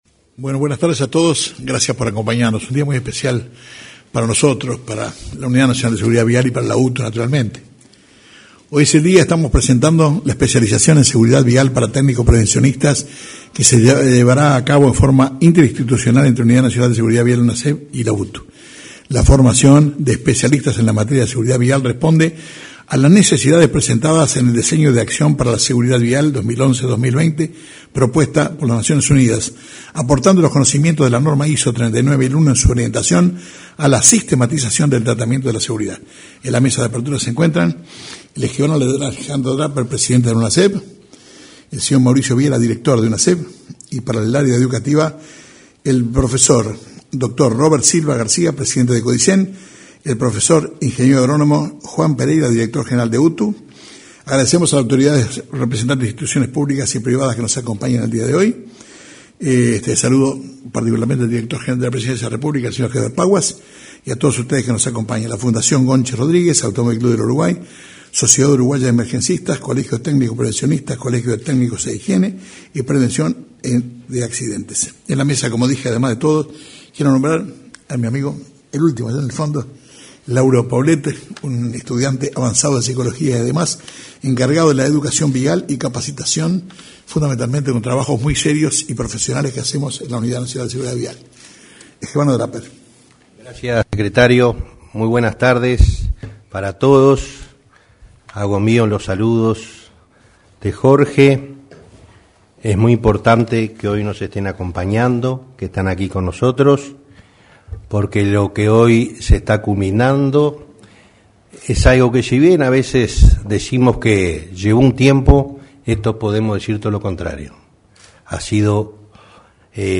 Este lunes 27, participaron del lanzamiento el presidente de Unasev, Alejandro Draper; acompañado por el director general de UTU, Juan Pereyra; el